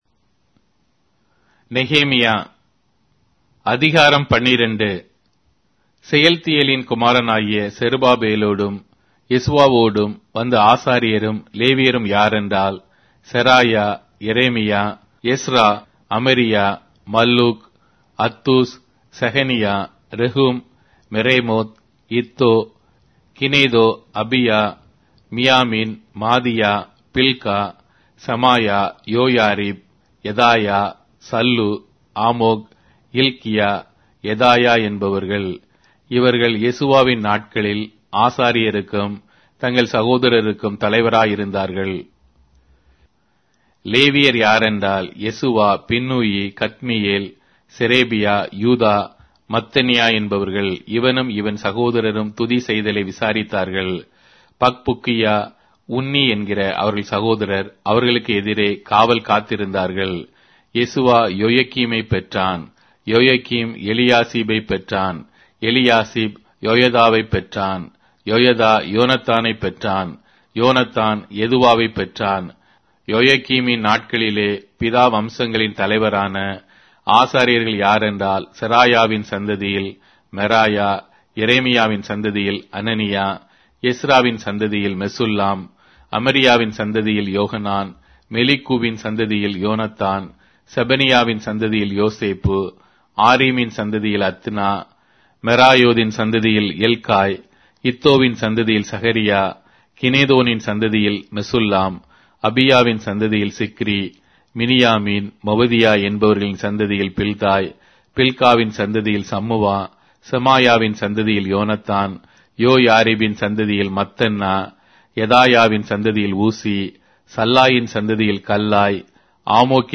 Tamil Audio Bible - Nehemiah 2 in Ervte bible version